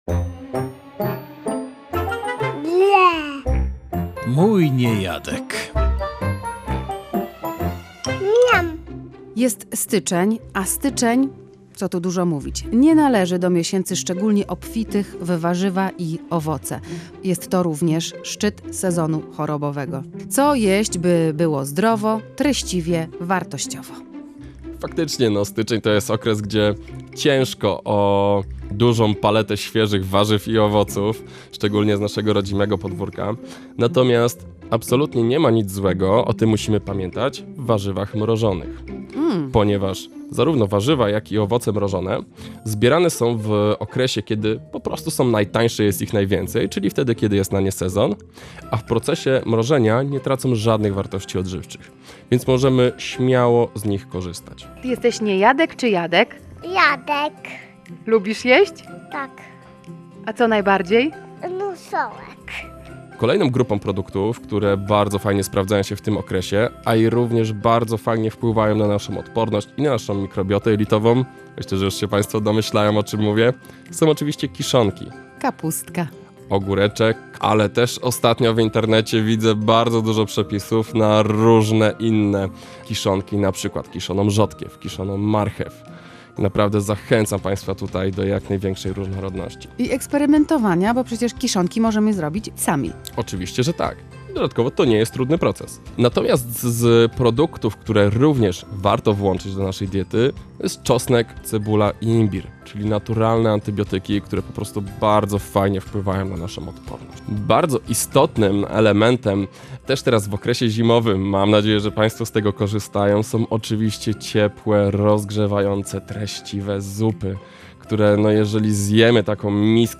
rozmawiała z dietetykiem pediatrycznym